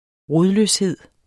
Udtale [ ˈʁoðløsˌheðˀ ]